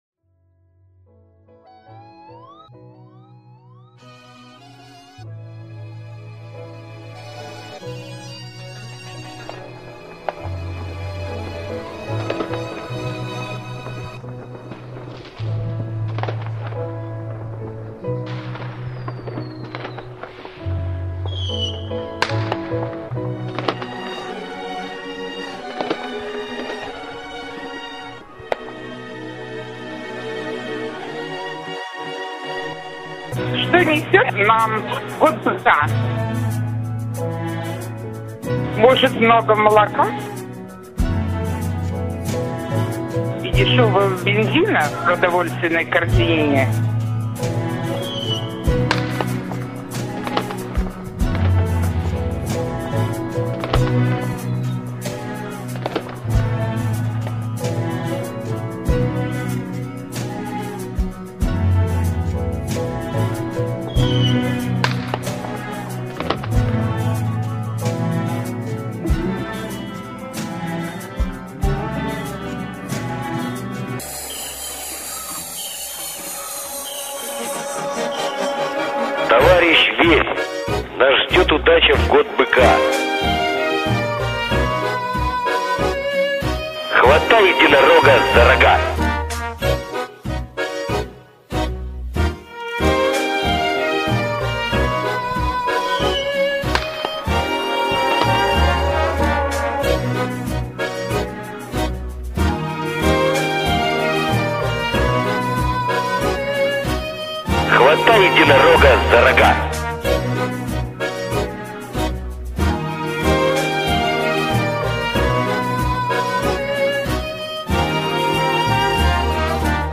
Основу музыкальных треков составили публичные высказывания, заявления и поздравления свердловских VIP-ов, в том числе губернатора Свердловской области Эдуарда Росселя.
Год Быка (депутаты свердловской Областной Думы Татьяна Тер-Терьян и Георгий Перский, уполномоченный по правам человека в Свердловской области Татьяна Мерзлякова) >>>